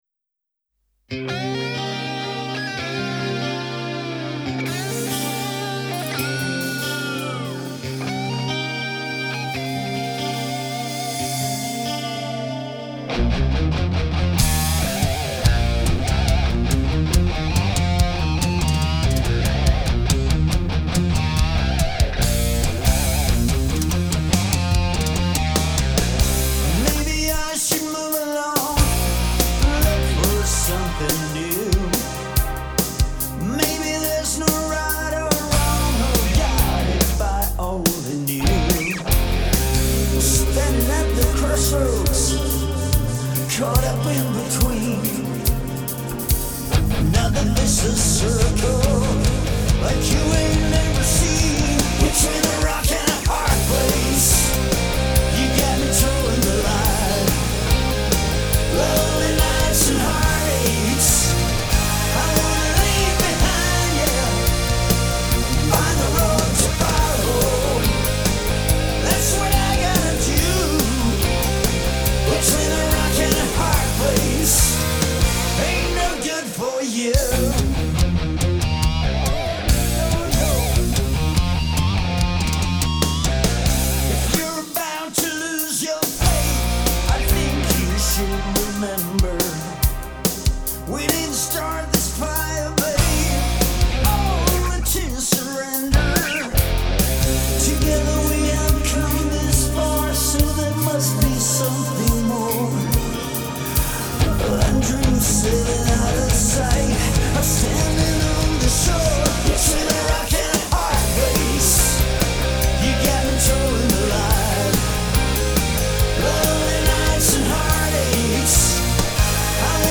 lead and rhythm guitar
lead and background vocals, rhythm guitar, percussion
Keyboards
Bass
Drums
Melodic Rock/AOR